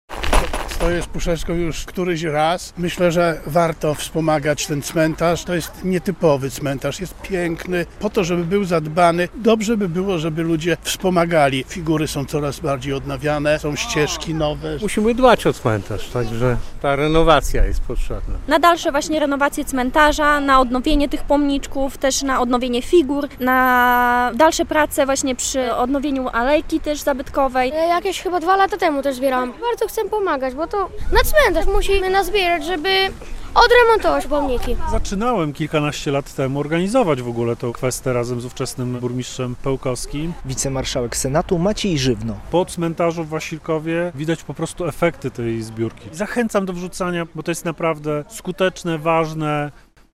Kwesta w Wasilkowie - relacja